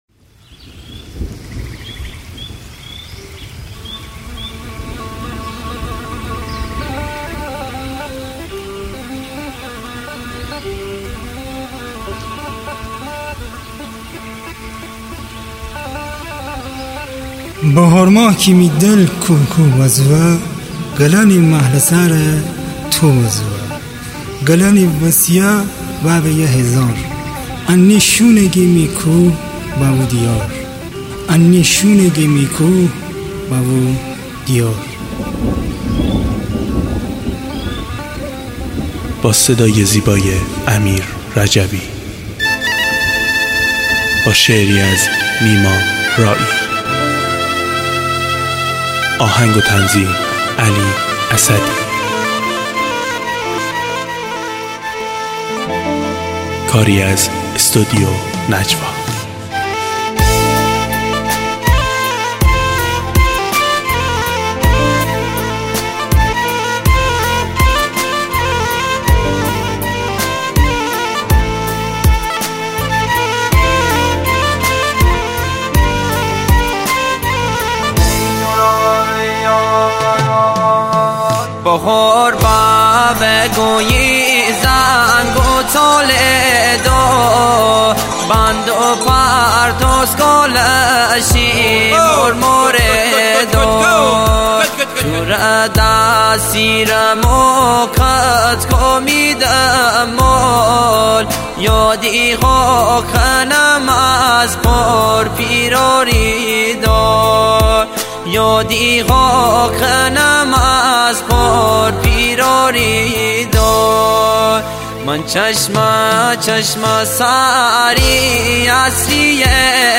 دانلود آهنگ مازندرانی